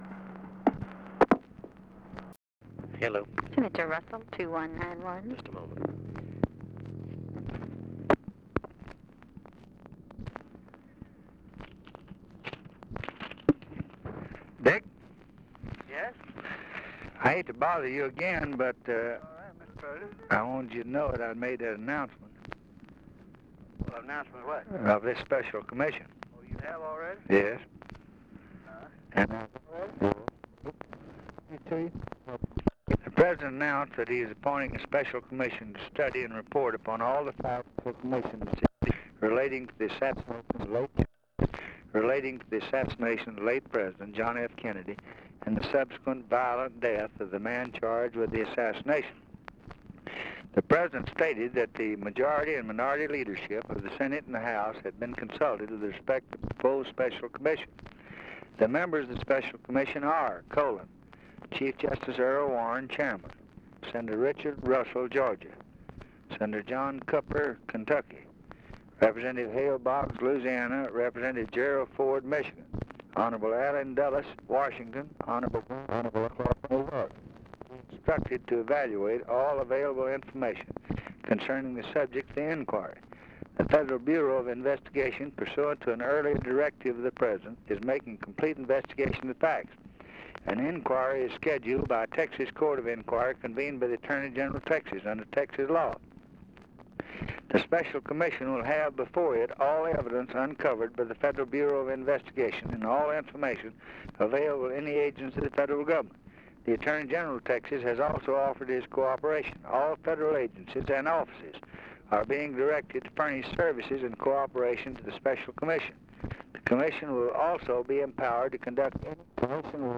Conversation with RICHARD RUSSELL, November 30, 1963
Secret White House Tapes | Lyndon B. Johnson Presidency Conversation with RICHARD RUSSELL, November 30, 1963 Rewind 10 seconds Play/Pause Fast-forward 10 seconds 0:00 Download audio Previous Conversation with WILLIAM MCC.